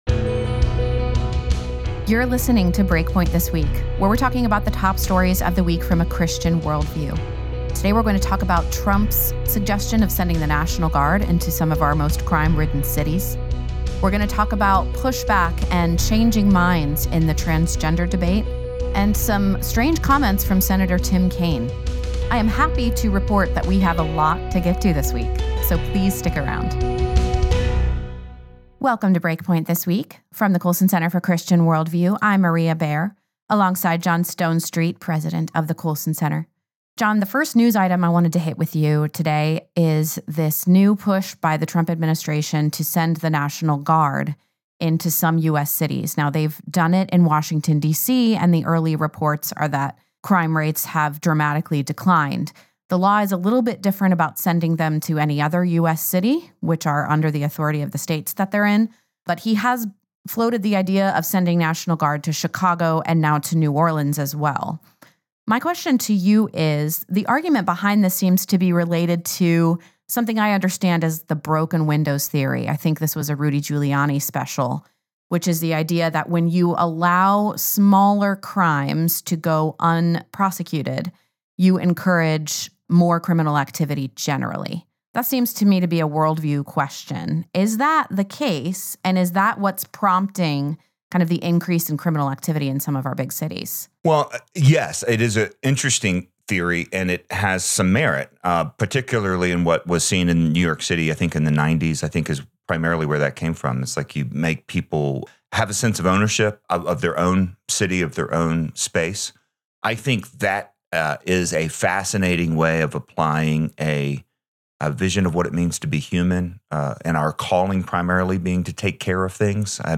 Deploying National Guard to American Cities, U.K. Comedian Arrested for Tweets, Malcolm Gladwell Confesses, Where Human Rights Come From, A Conversation with Jack Phillips, and “Shiny, Happy People”